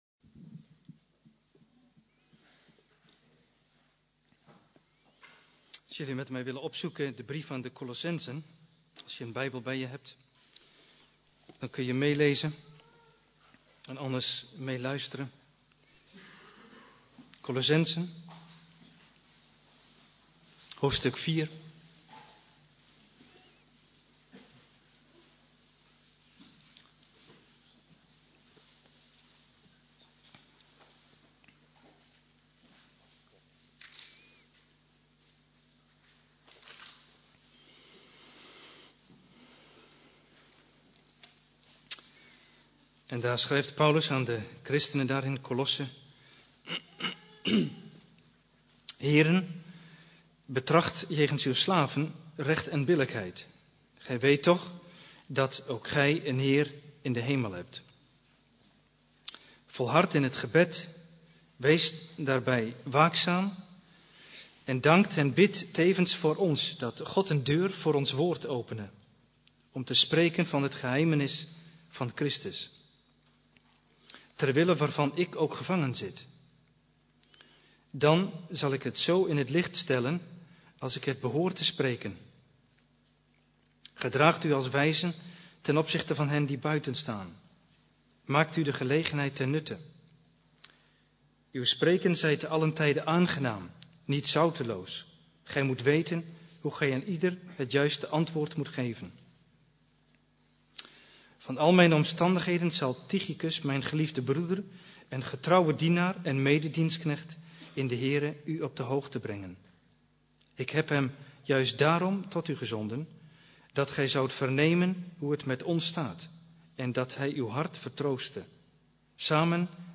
Dienstsoort: Eredienst